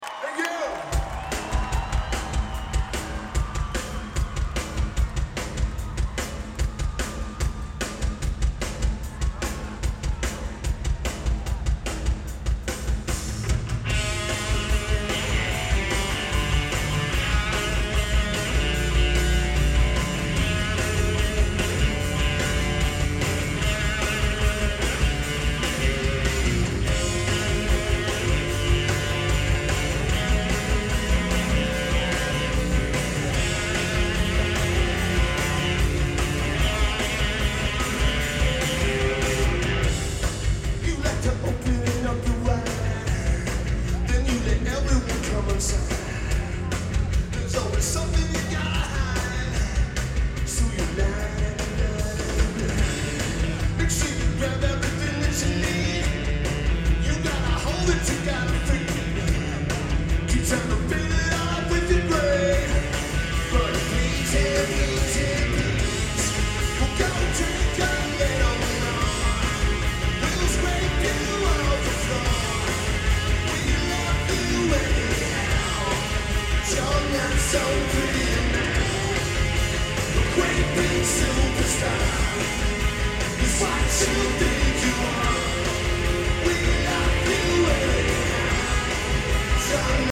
Frank Erwin Center
This is a better recording than the other source.